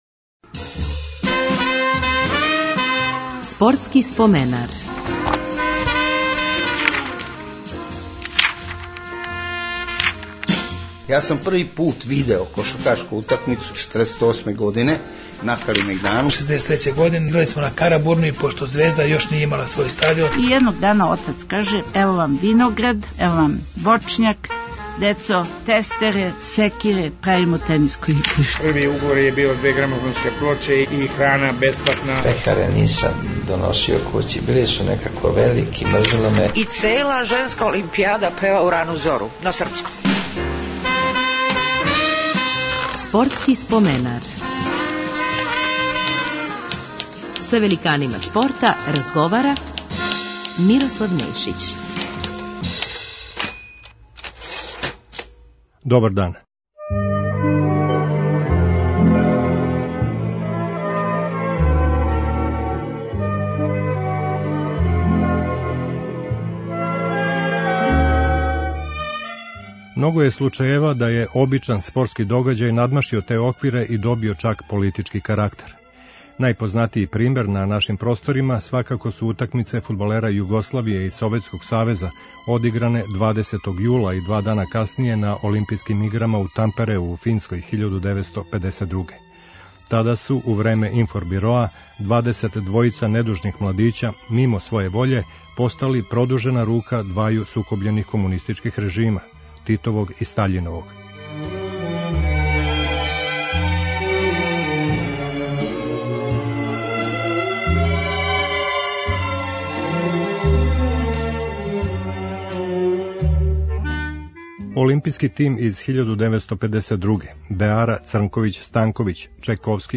у емисији ће бити коришћени и записи из нашег тонског архива актера антологијских утакмица